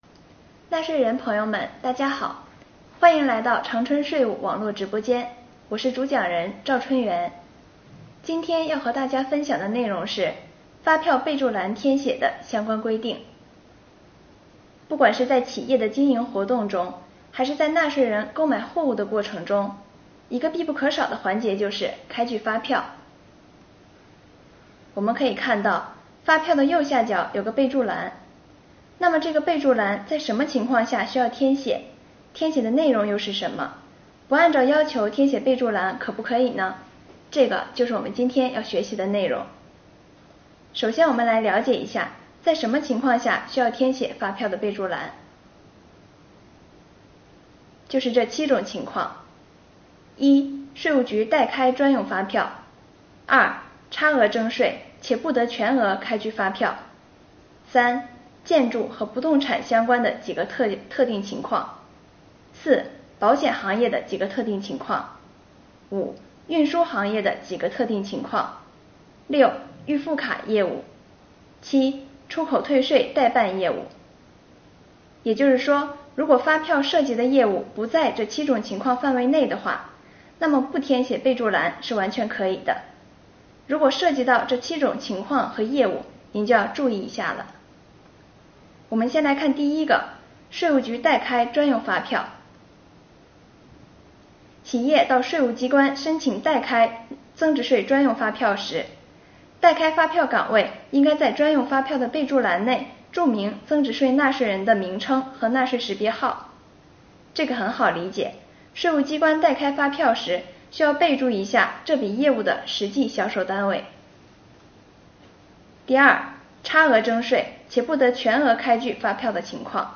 2020年第37期直播回放：发票管理系列政策之一:发票备注栏填写相关规定。